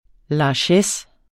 Udtale [ lɑˈɕεs ]